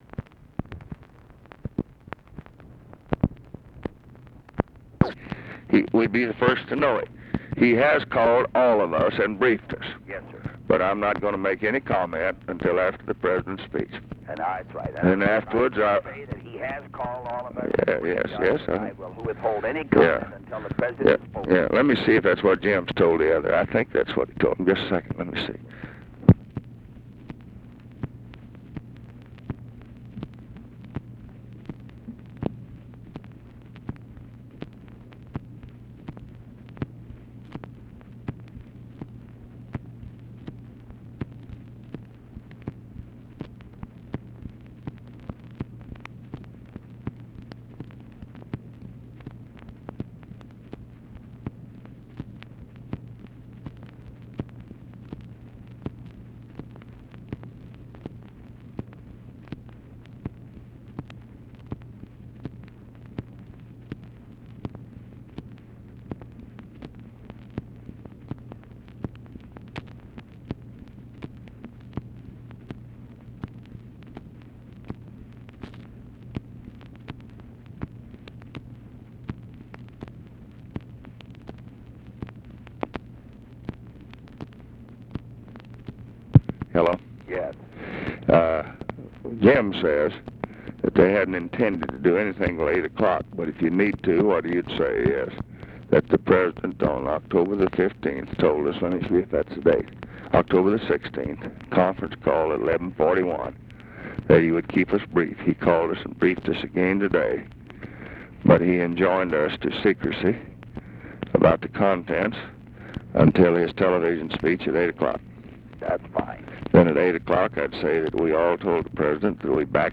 Conversation with HUBERT HUMPHREY, October 31, 1968
Secret White House Tapes